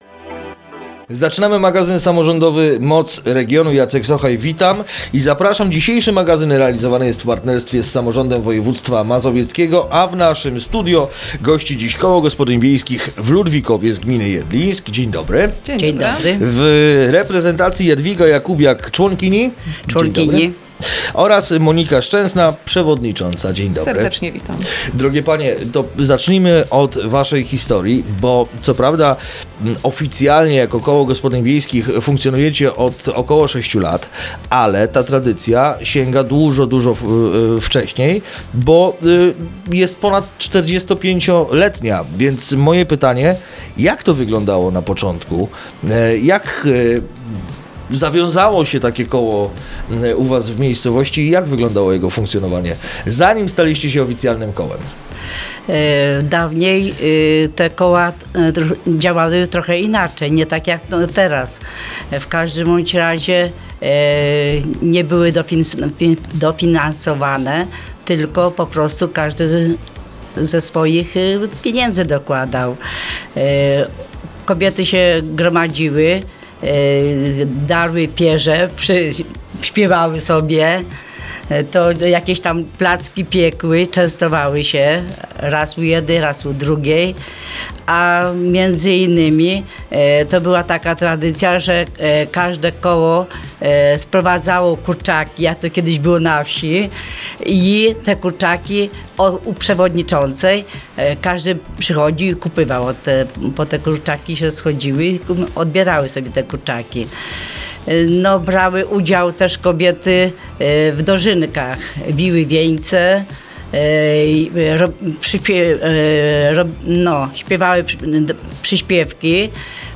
Rozmowa dostępna jest także na facebookowym profilu Radia Radom: